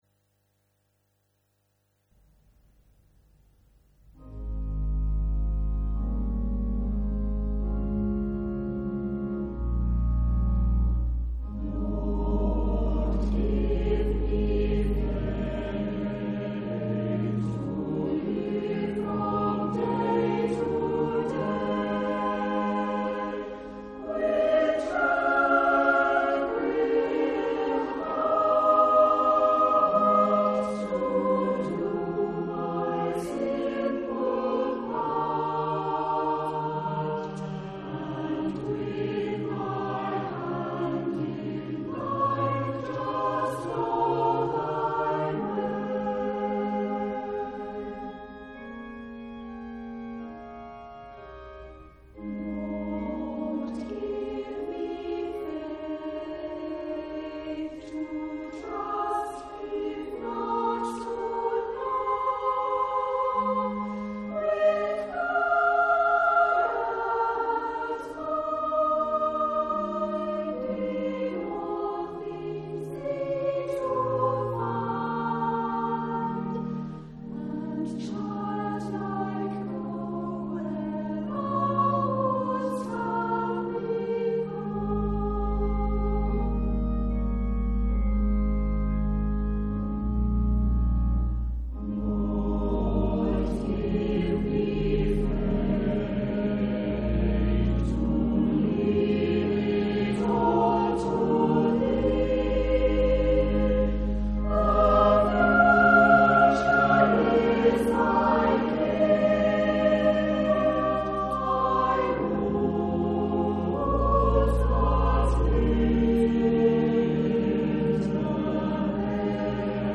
The choir in action
a short anthem